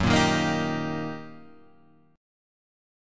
Eb/Gb chord